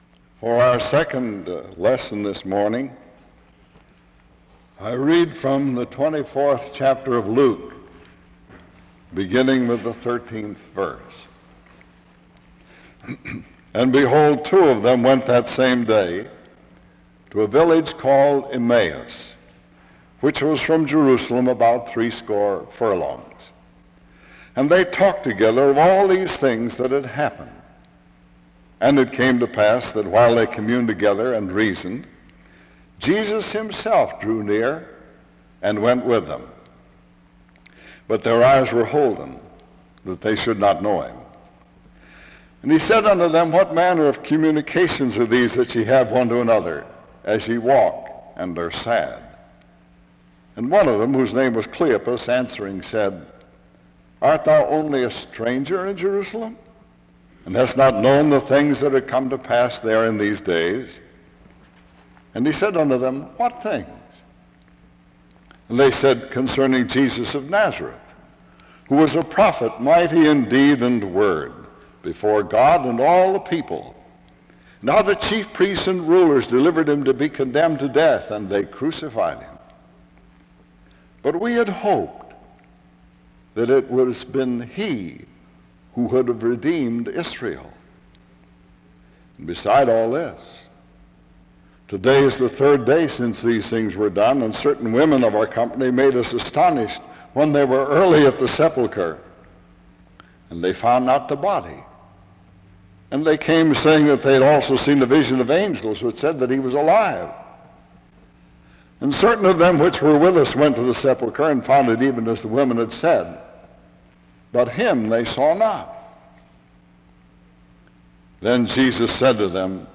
The service begins with a reading of Luke 24:13-25 (0:00-2:07).
He explains why hope means so much to the Church, especially during the holy week of resurrection (8:17-18:37). He concludes by illustrating that Jesus is the hope of the world (18:38-21:00). He closes in prayer (21:01-22:21).